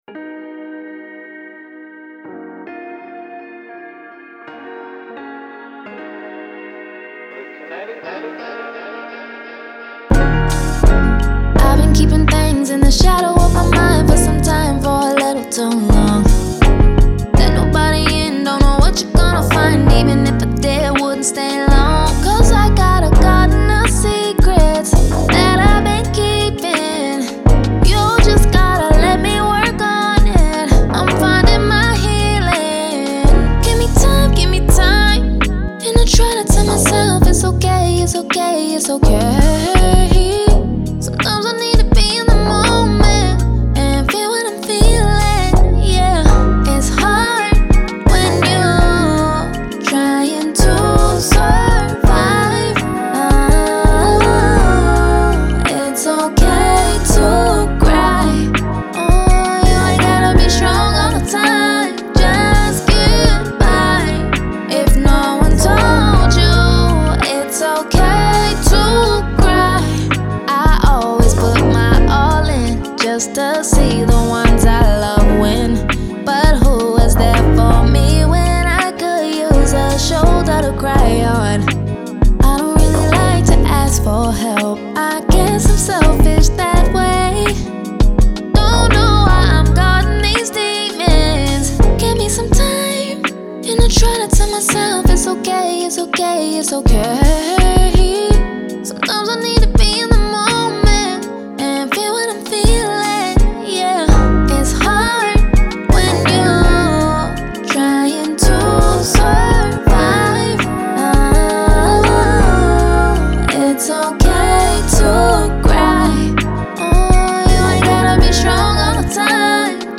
R&B, Soul
Eb Min